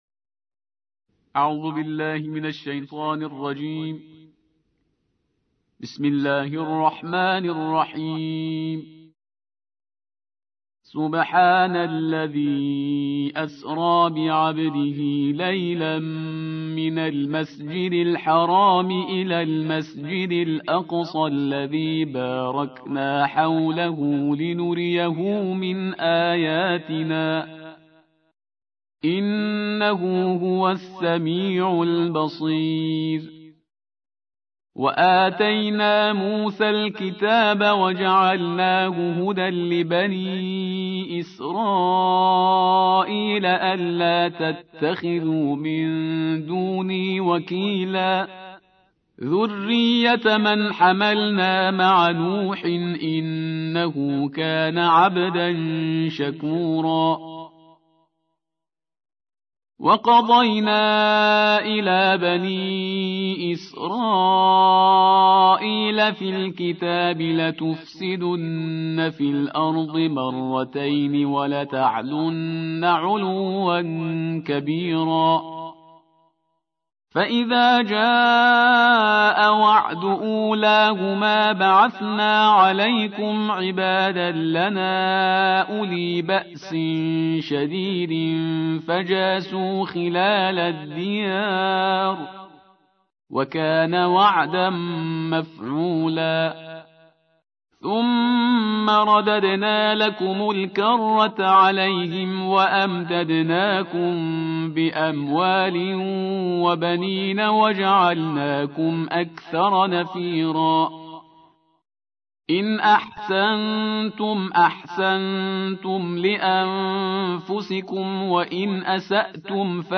الجزء الخامس عشر / القارئ